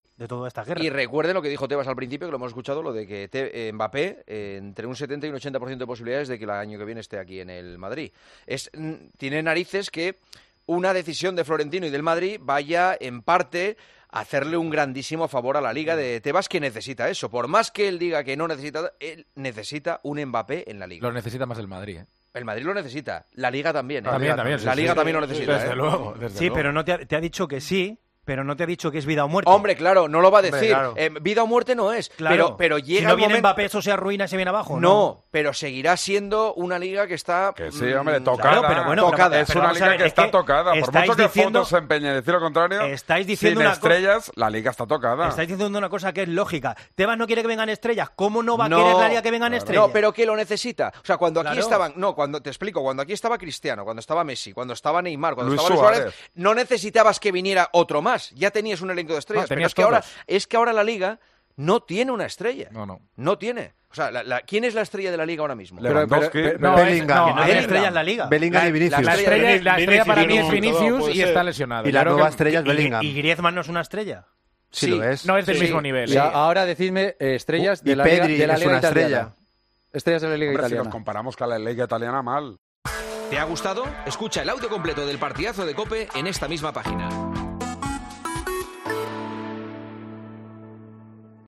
AUDIO: El director y presentador de El Partidazo de COPE analiza las necesidad de LaLiga después de su entrevista a Javier Tebas en Movistar+.